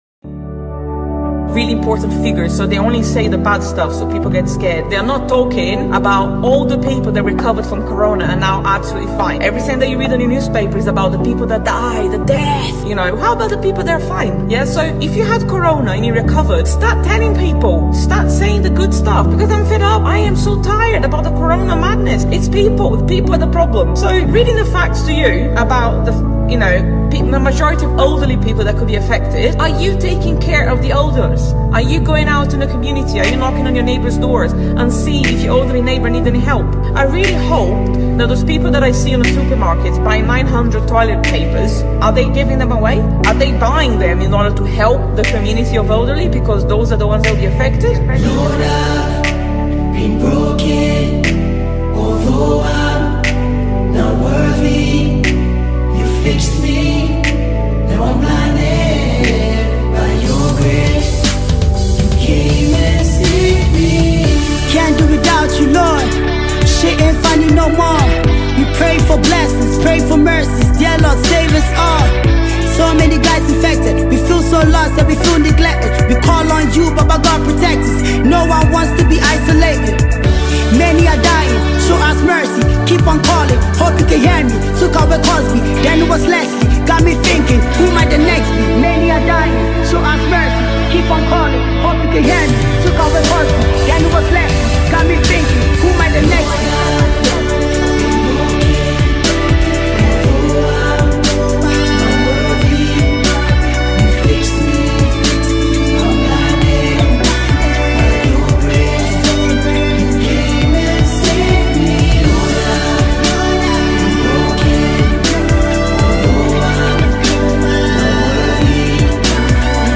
Ghanaian rapper and singer